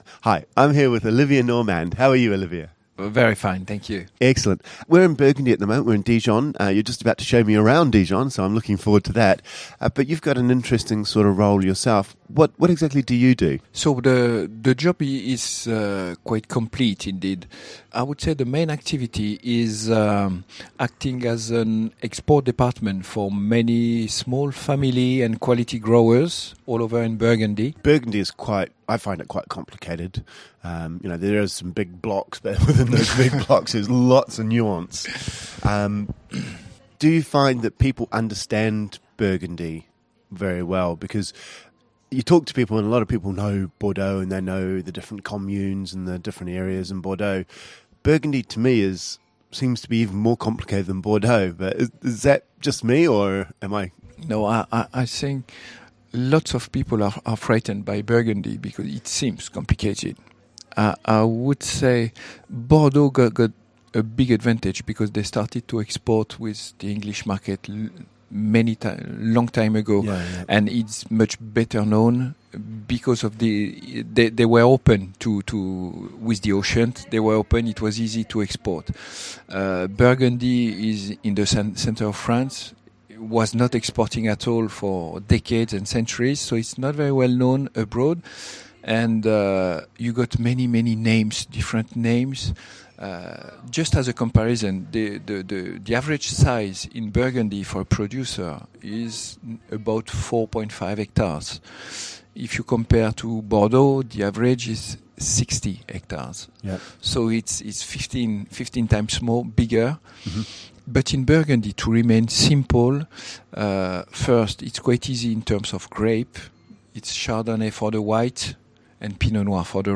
In this series of Short interviews we find out about the key regions in Burgundy and the different grape varieties and style of wine produced in Beaujolais.